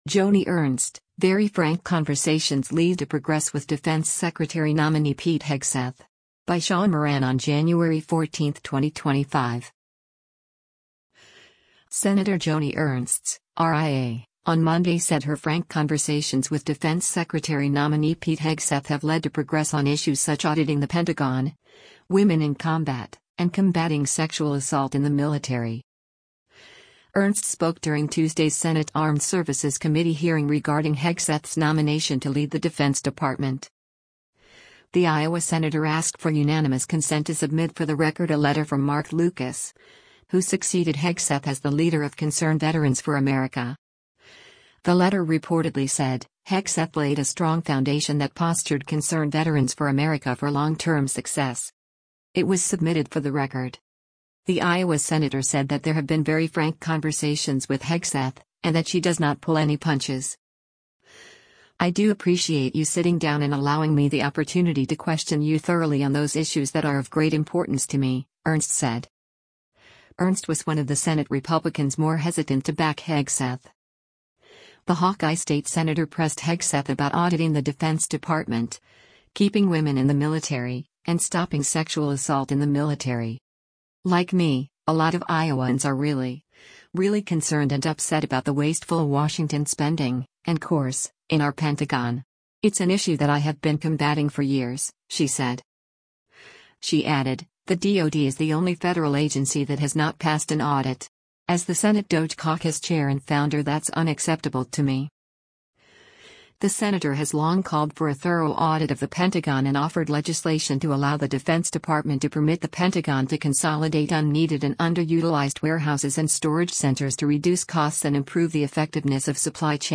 Ernst spoke during Tuesday’s Senate Armed Services Committee hearing regarding Hegseth’s nomination to lead the Defense Department.